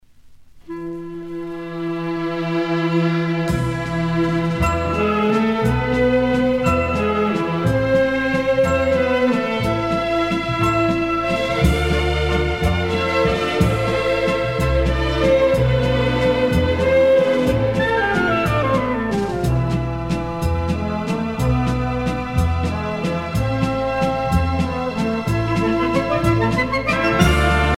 danse : valse viennoise
Pièce musicale éditée